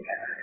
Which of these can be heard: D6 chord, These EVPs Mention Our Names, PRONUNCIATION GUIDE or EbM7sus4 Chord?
These EVPs Mention Our Names